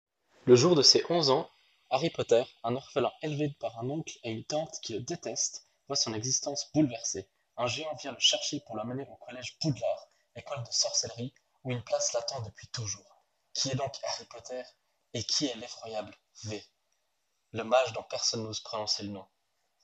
Côté microphone, ce n’est pas folichon. Mais il faut garder à l’esprit que nous sommes sur des petits microphones et qu’il ne faut pas trop en demander.
test-ecouteurs-.wav